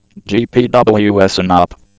gpws-inop.wav